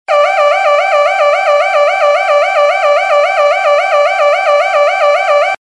Alarma
Efectos de sonido / Tonos Divertidos Alarma 16 noviembre, 2012 - por admin Efecto de sonido de una alarma Alarma Accede directamente para descargarte ese y otros tonos gratis para movil. tonosmovil.net_alarma.mp3
tonosmovil.net_alarma.mp3